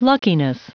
Prononciation du mot luckiness en anglais (fichier audio)
Prononciation du mot : luckiness